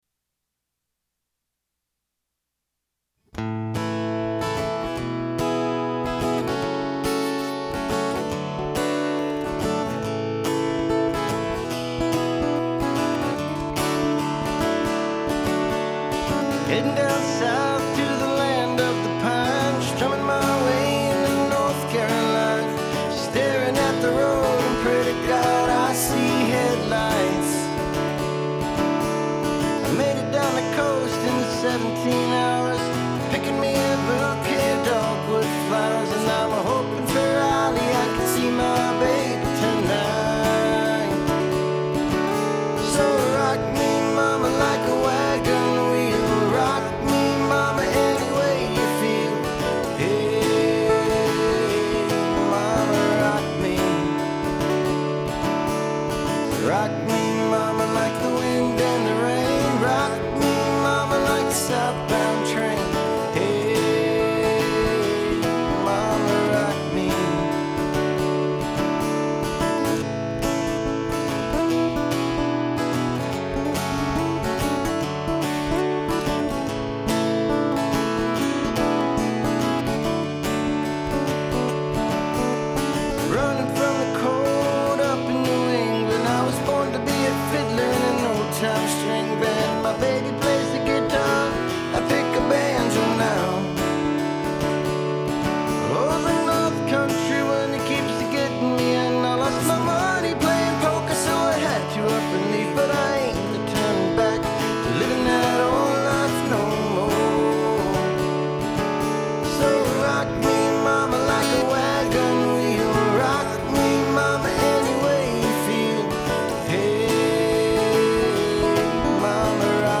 Tried re-recording the guitars last night and didn't get anything I was happy with yet.
I'll take critique on anything. The mix, the singing, the playing.